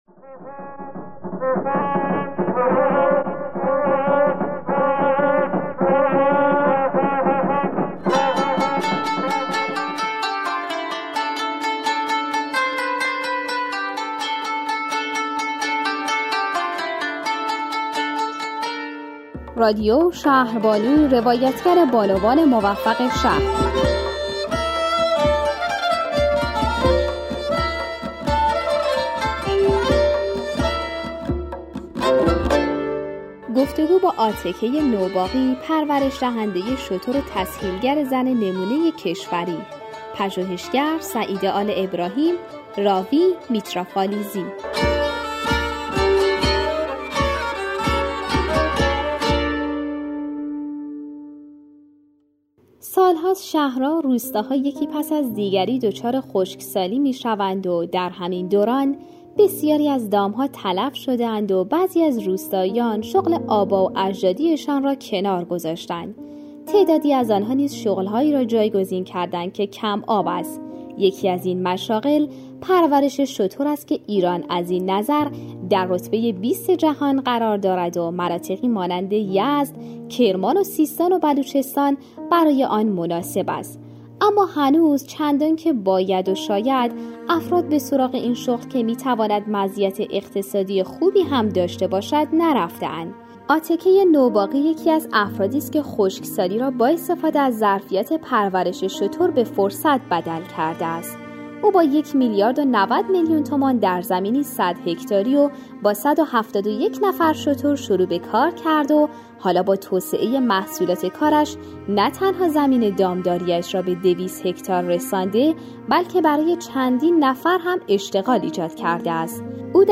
گفتگو